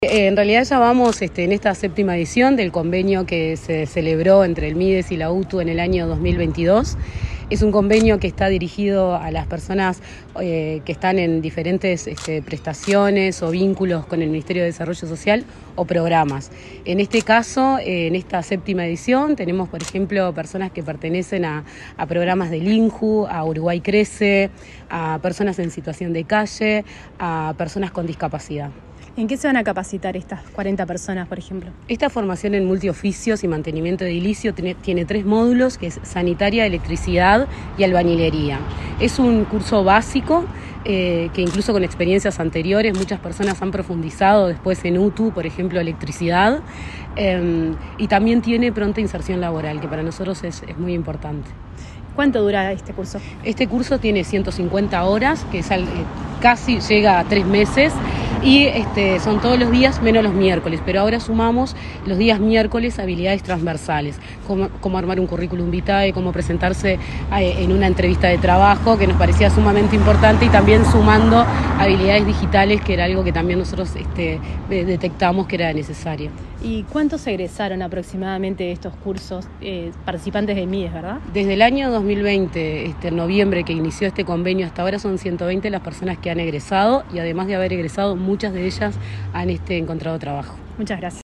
Entrevista a la directora de Promoción Sociocultural del Mides, Rosa Méndez
La directora de Promoción Sociocultural del Ministerio de Desarrollo Social (Mides), Rosa Méndez, dialogó con Comunicación Presidencial sobre el